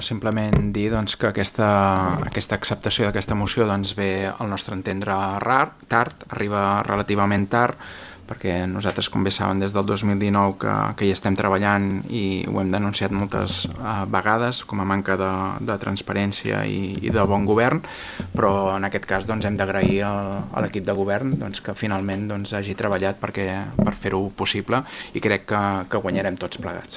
El regidor d’ERC Xavier Ponsdomènech ha recordat que, segons el Reglament Orgànic Municipal (ROM) i la normativa vigent, els municipis de més de 20.000 habitants —una xifra que Calella va assolir i consolidar el 2023— han de celebrar com a mínim un ple ordinari al mes. Per això, ha celebrat l’acord, tot i lamentar que arribi amb retard.